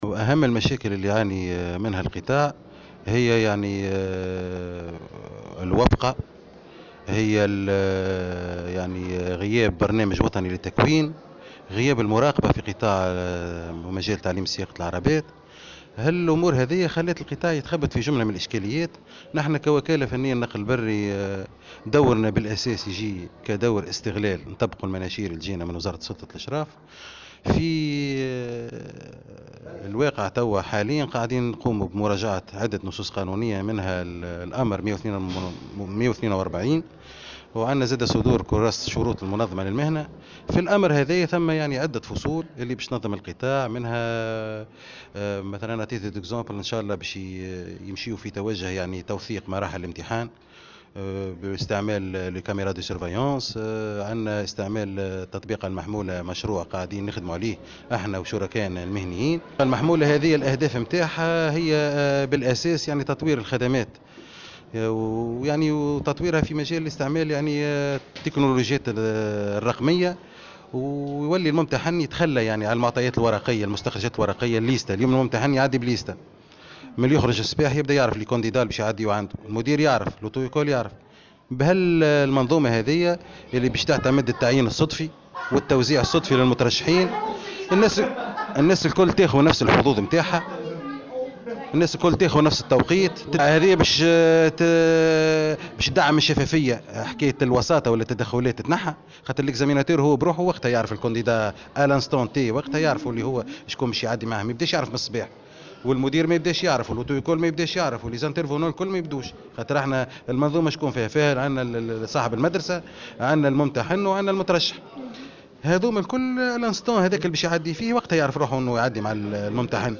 وأضاف في تصريح اليوم لمراسل "الجوهرة أف أم" أنه سيتم مراجعة عديد الفصول القانونية المنظمة للقطاع من ذلك الأمر عدد 142 و توثيق مراحل الامتحان باستعمال كاميرات مراقبة وذلك للتصدي لكل أشكال الوساطة ولتدعيم الشفافية، وفق تعبيره.